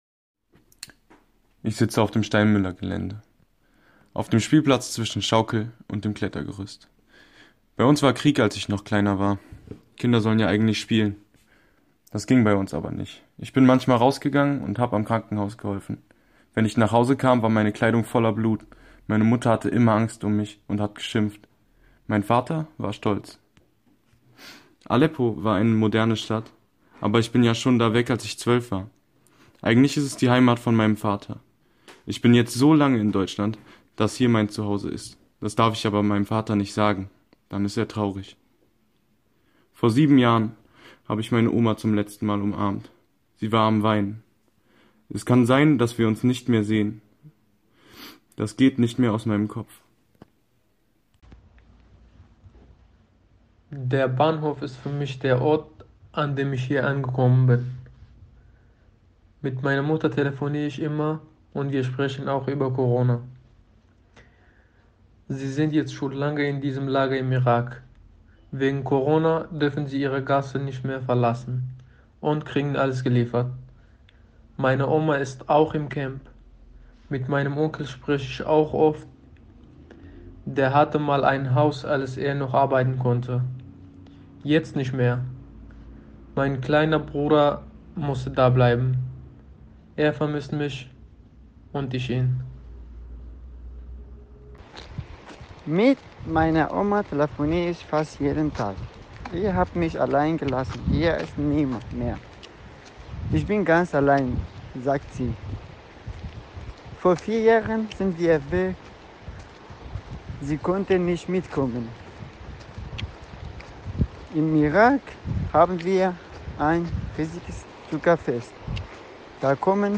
Hier zu hören sind nun zunächst die Stimmen der Jugendlichen mit kurzen Ausschnitten aus dem, was später – hoffentlich bald - in einem Audio Walk in der Gummersbacher Innenstadt zu hören sein wird.
Zum Zuhören, hinsetzen, genießen - hier der akustische Spaziergang durch 14 verkürzte Lebensgeschichten.